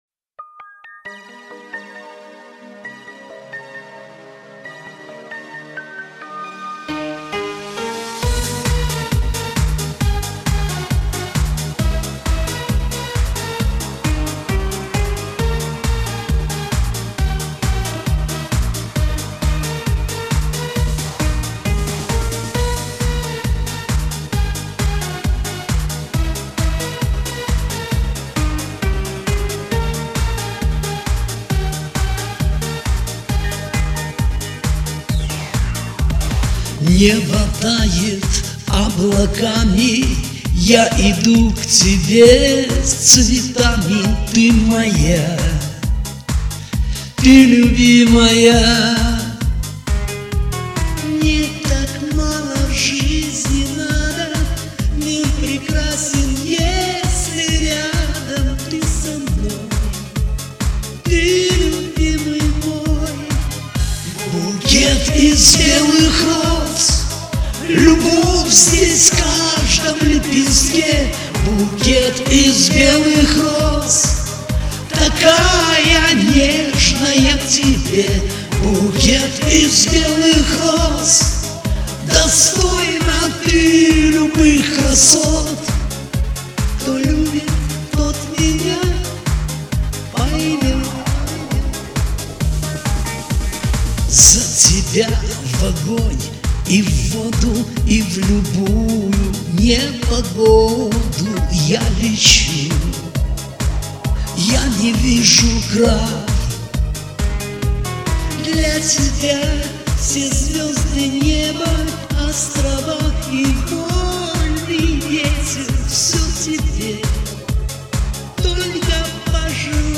1. Ответчик был без партнера.
3. Качество записи оставляет желать лучшего.